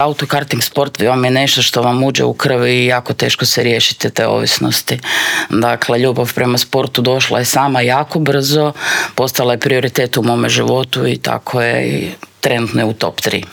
U intervjuu Media servisa o tome su pričale naše gošće